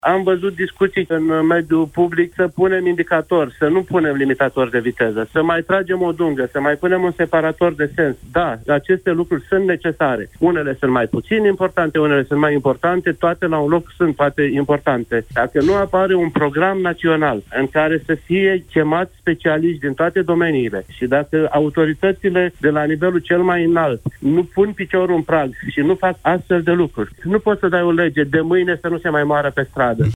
Fostul pilot de raliuri Titi Aur, fondatorul unei școli de conducere defensivă, a spus în emisiunea Deşteptarea la Europa FM că astfel de măsuri sunt necesare rapid: